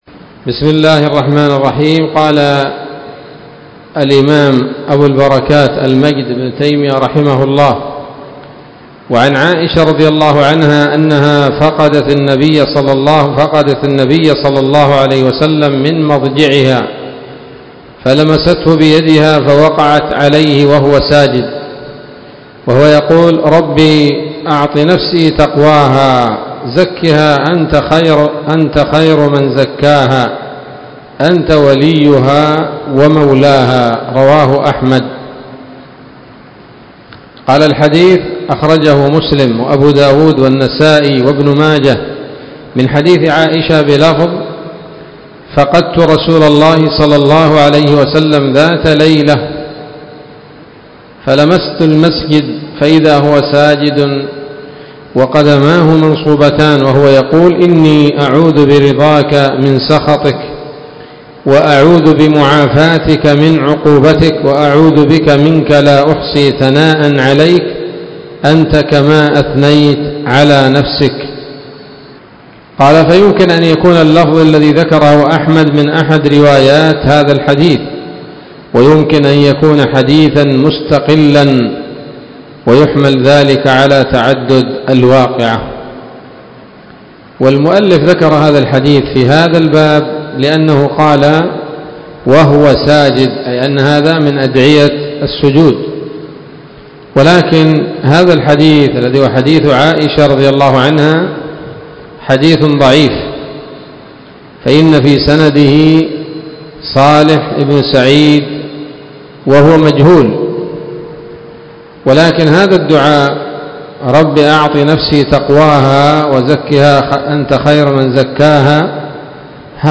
الدرس التاسع والثمانون من أبواب صفة الصلاة من نيل الأوطار